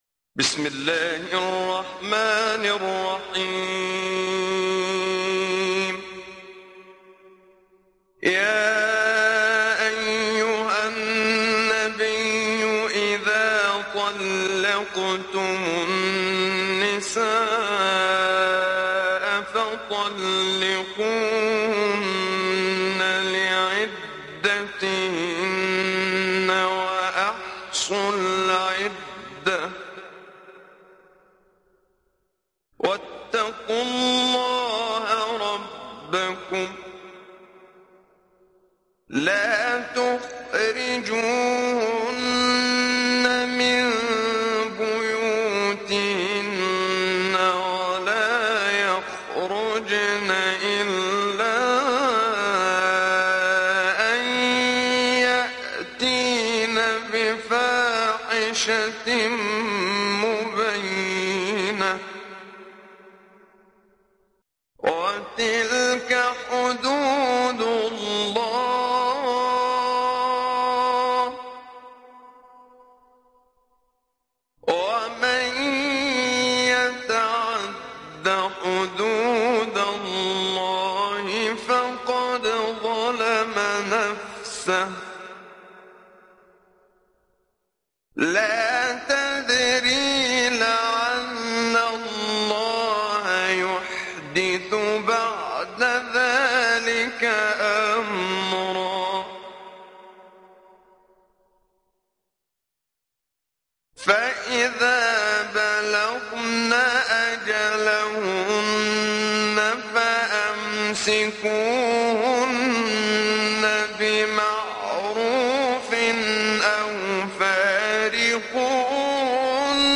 ডাউনলোড সূরা আত-ত্বালাক Muhammad Siddiq Minshawi Mujawwad